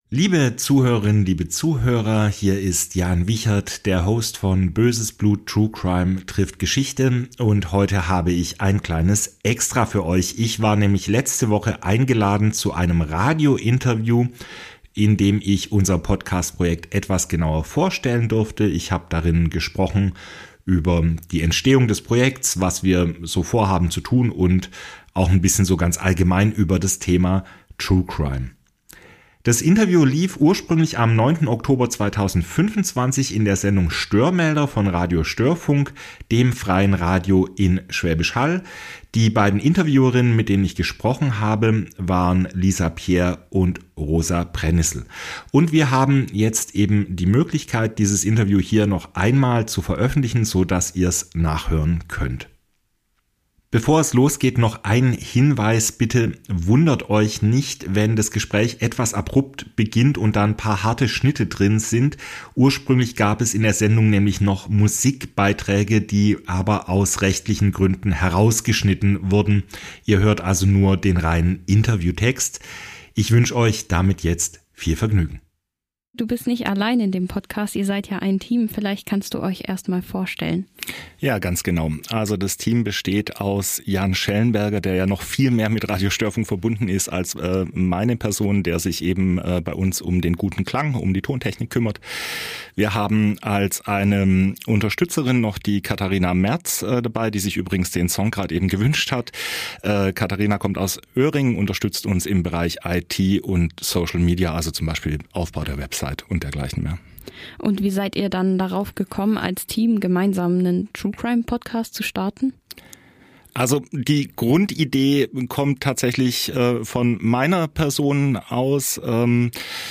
Das Interview kann hier noch einmal nachgehört werden. Die Musiktitel wurden aus urheberrechtlichen Gründen entfernt.